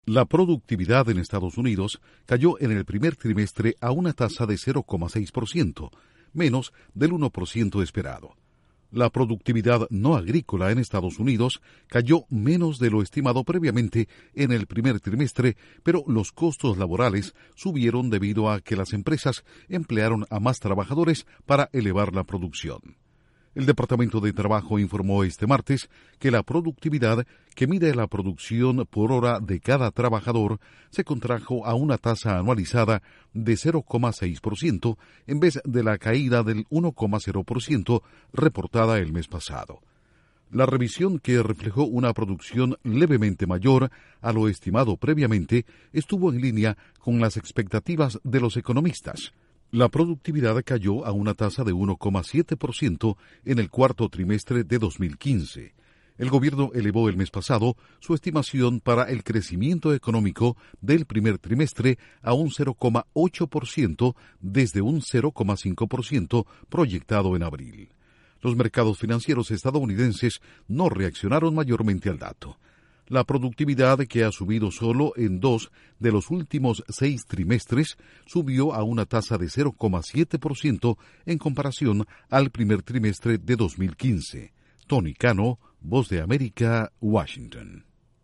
La productividad en Estados Unidos se contrae modestamente. Informa desde la Voz de América